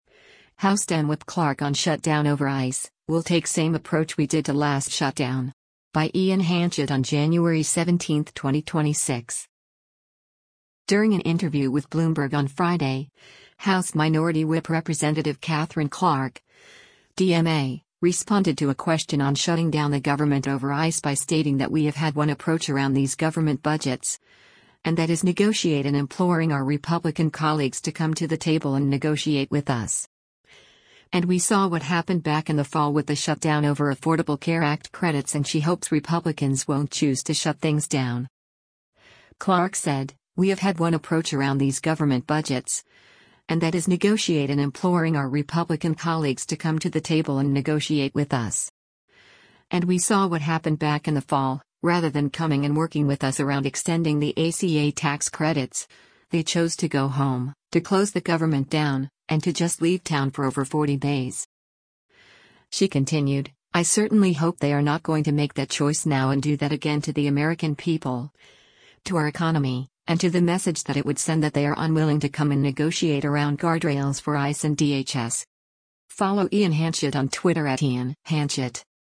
During an interview with Bloomberg on Friday, House Minority Whip Rep. Katherine Clark (D-MA) responded to a question on shutting down the government over ICE by stating that “We have had one approach around these government budgets, and that is negotiate and imploring our Republican colleagues to come to the table and negotiate with us. And we saw what happened back in the Fall” with the shutdown over Affordable Care Act credits and she hopes Republicans won’t choose to shut things down.